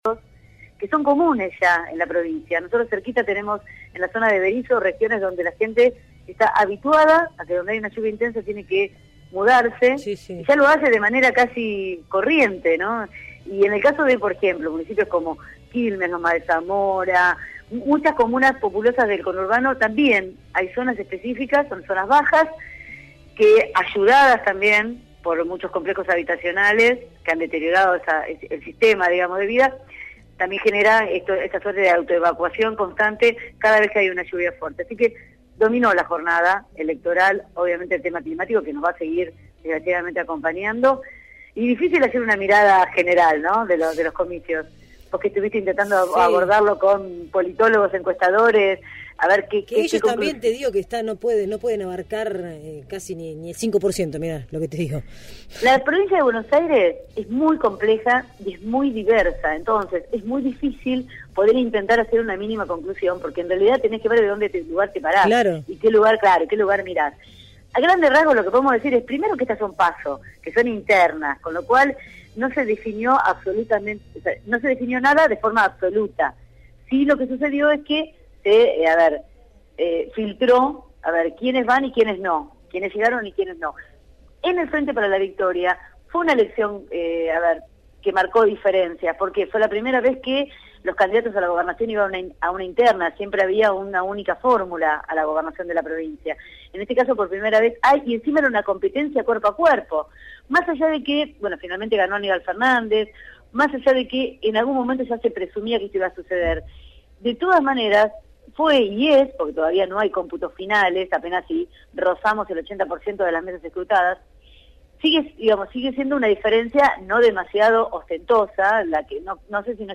desde Casa de Gobierno de Buenos Aires